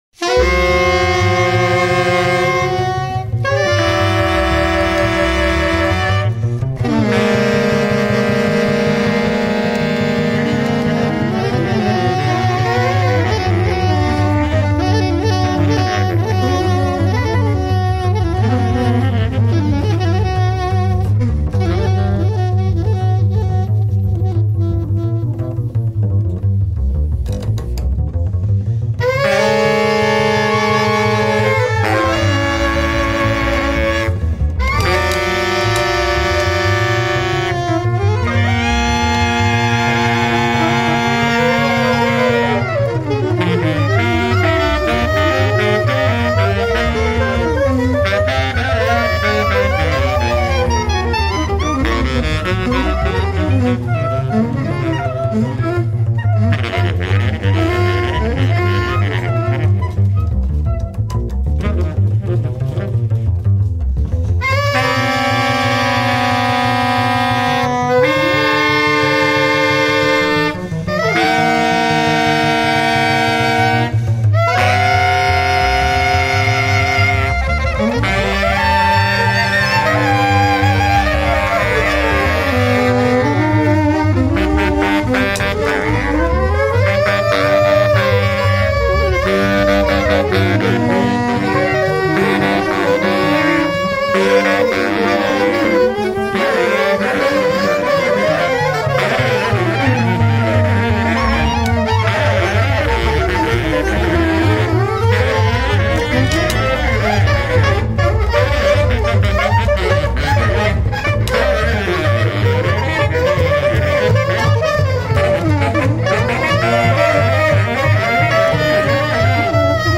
violin
baritone sax, tarogato
soprano sax
double bass
at Villa Parodi, Genova on August 1, 2008